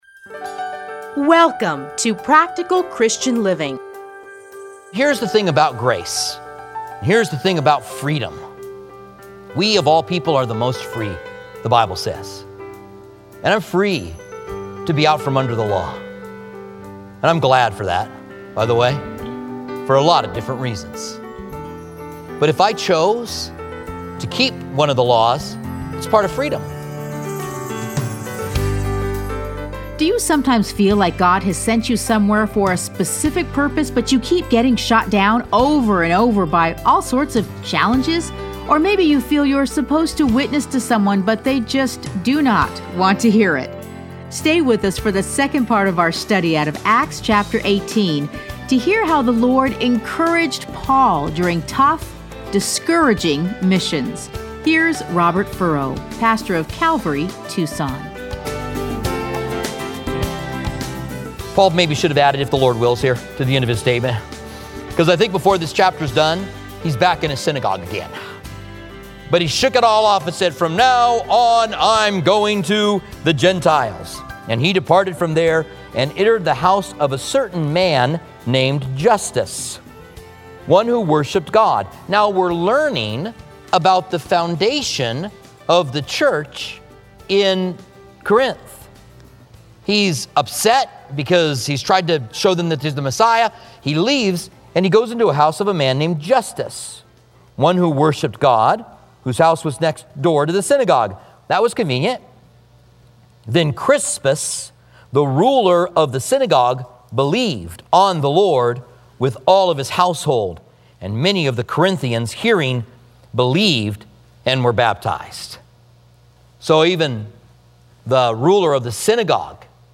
Listen to a teaching from Acts 18:1-28.